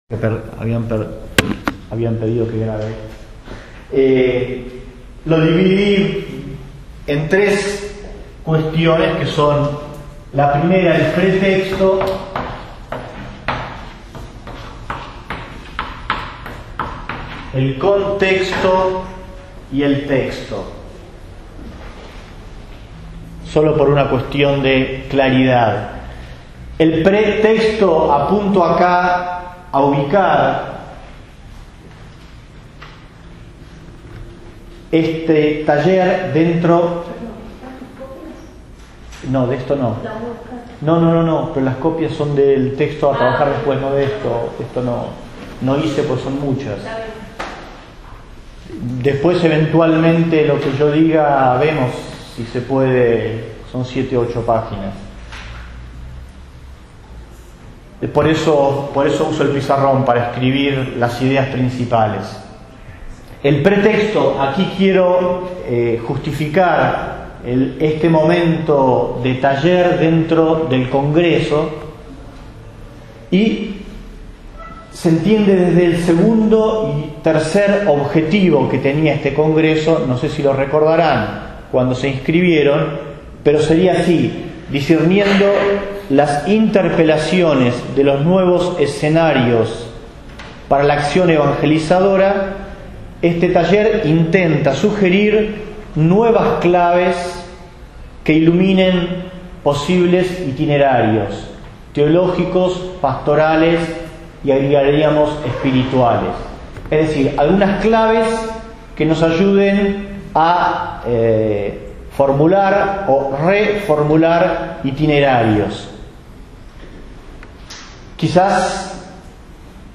nel Workshop "Espacios urbanos"